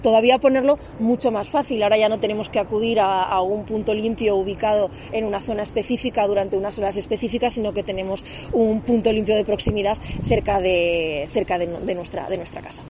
La consejera de Medio Ambiente explica qué residuos se pueden dejar en los nuevos puntos limpios